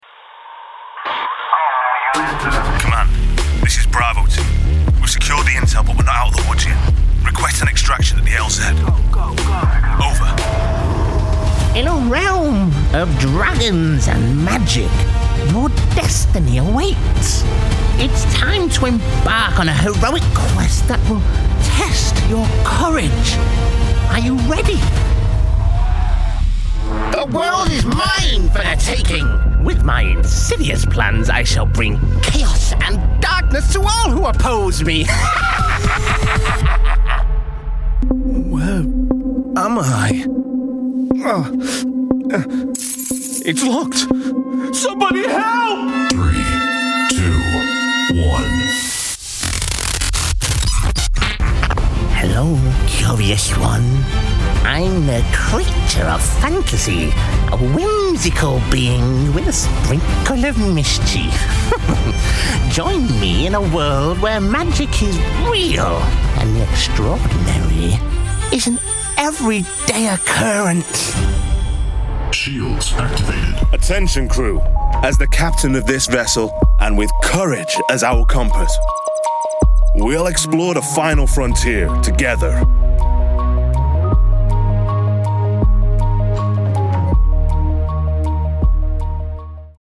Animation / Gaming